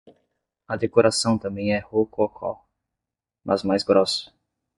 Pronounced as (IPA) /ʁo.koˈkɔ/